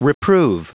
Prononciation du mot reprove en anglais (fichier audio)
Prononciation du mot : reprove